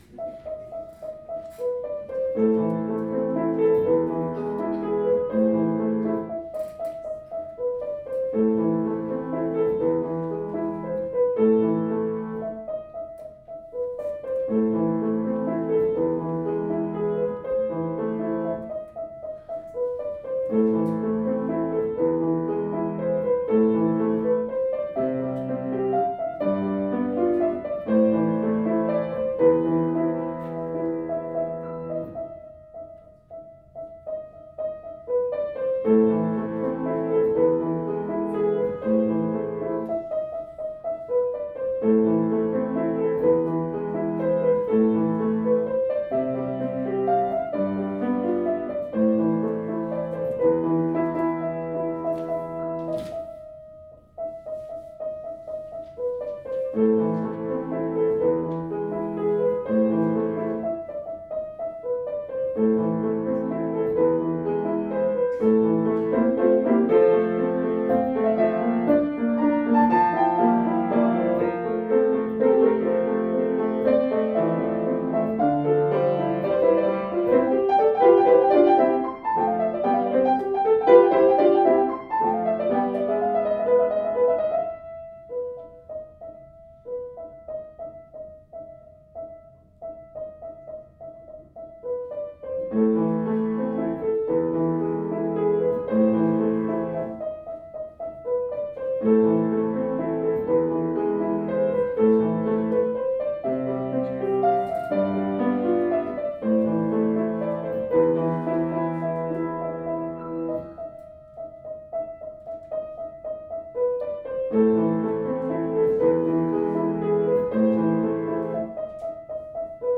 I used it to record the guy's recital (he played Für Elise), but I also recorded video with the iPhone. The iPhone audio distorts in few places and has muddier bass.
The H2N did pick up the sound of my feet moving, but that could have been my mistake for putting it on the floor.